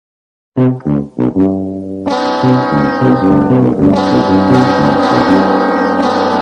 Tuba Berserk Meme Sound Effect Free Download
Tuba Berserk Meme